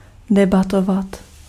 Ääntäminen
Synonyymit talks discourse agitate discuss contest contend Ääntäminen US UK : IPA : /dɪˈbeɪt/ Lyhenteet ja supistumat (laki) Deb.